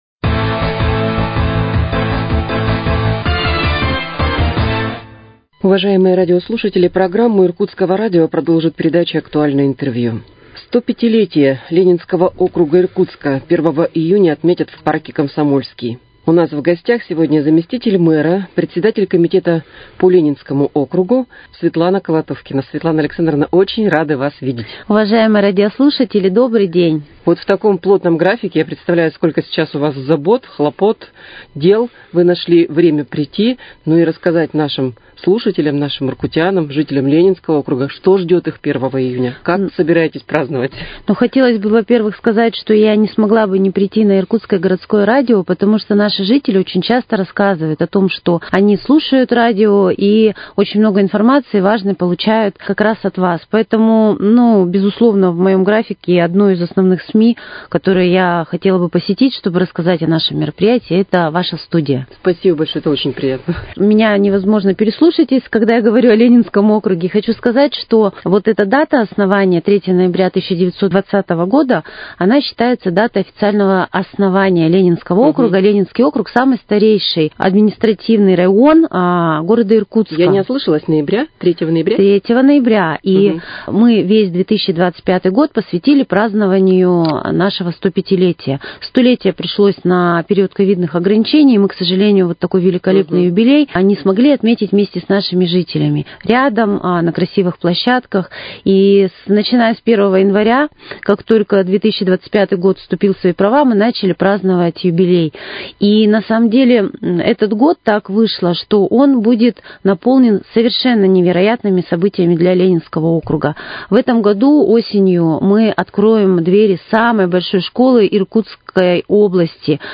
Актуальное интервью: О программе мероприятий к 105-летию Ленинского округа